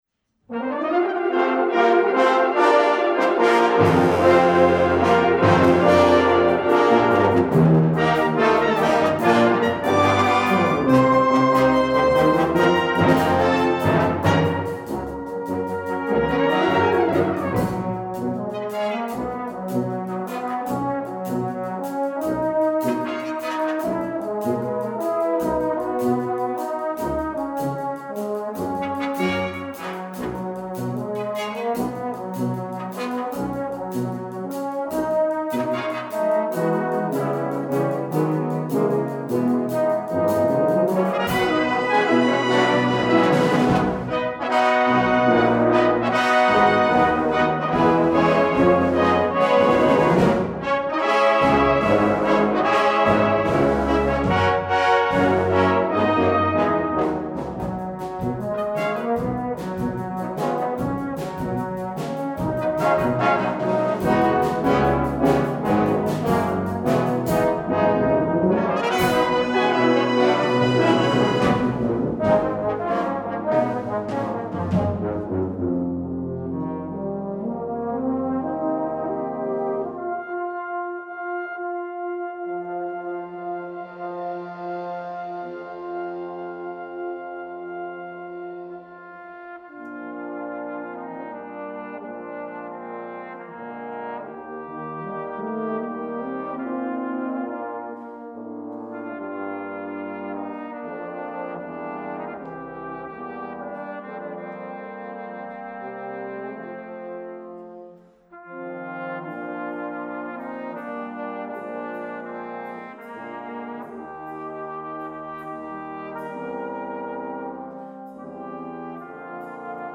Gattung: Ouvertüre
5:00 Minuten Besetzung: Blasorchester PDF